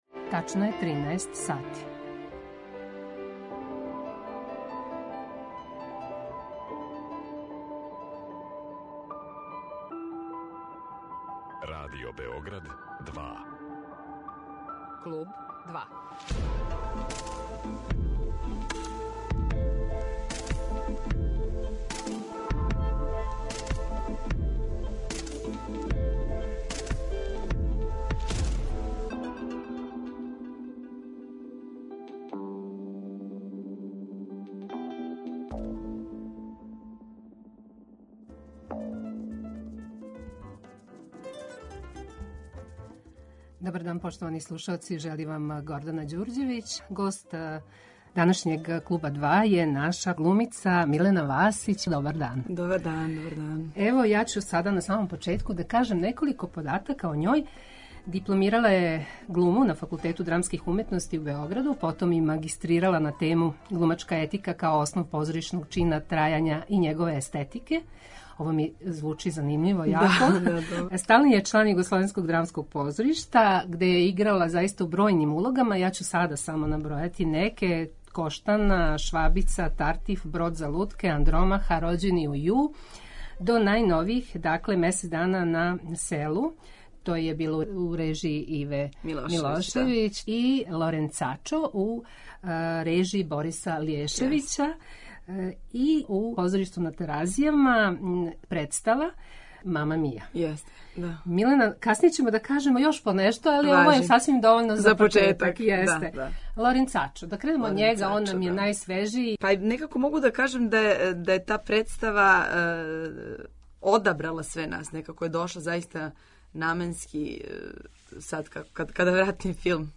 Гост је глумица Милена Васић
Прича о тираноубици Лоренцачу послужила је за разговор о позоришту, али и о револуцијама и мењању света.